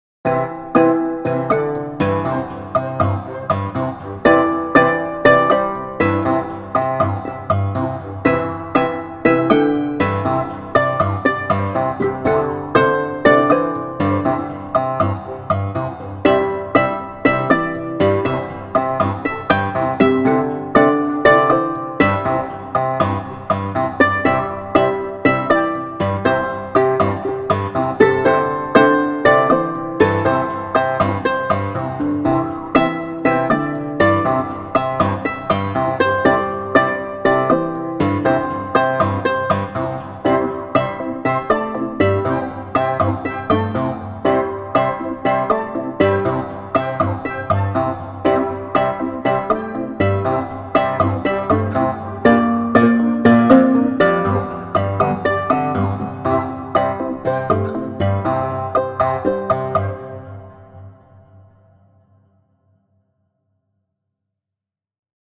Guitar based music for puzzle and casual.